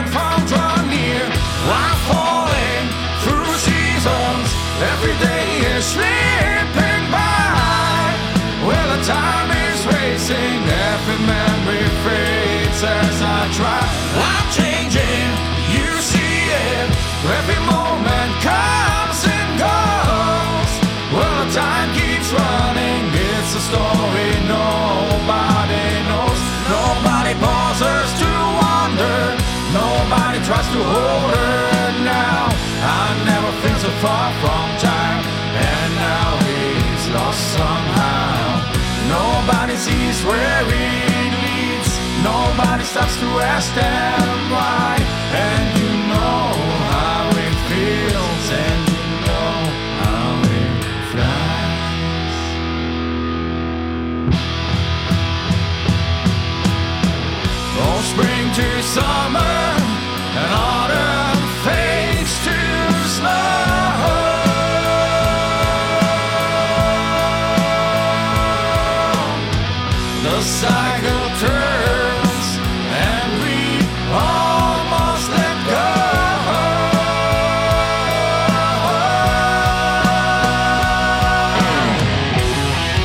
Falling through Seasons - Rock song
Ich finde da ist unten rum zuviel los da du ja schon ne tiefe stimme hast da oben muss wat kommen
Also im Solo Part habe ich nochmal das Schlagzeug etwas angepasst.
Backing Vocals habe ich mit Cubase Variaudio versucht zu erstellen. Das Ergebnis ist eher so lala (klingt etwas nach Vocoder), ich bin nicht so geübt im Singen von Harmonien.